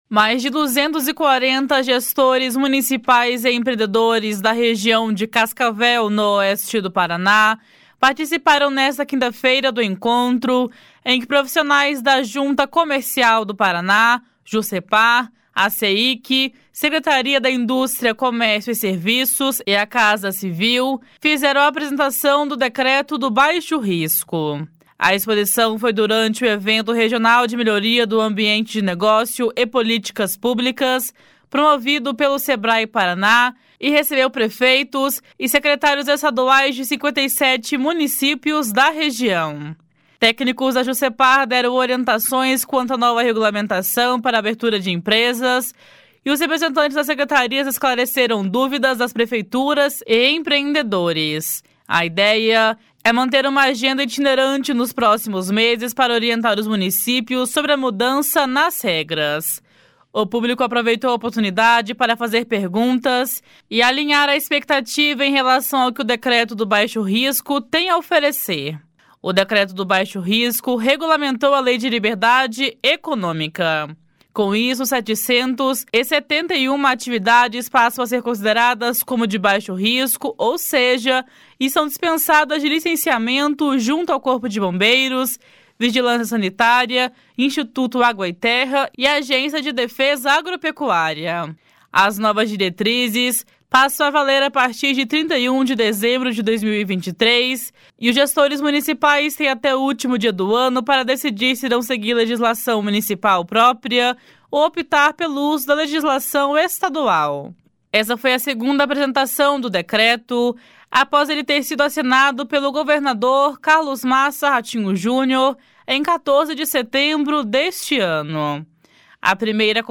Para o diretor-geral da Seic, Christiano Puppi, o decreto, e também os encontros para divulgá-lo, esclarecer e orientar gestores municipais são importantes conquistas. // SONORA CRISTIANO PUPPI //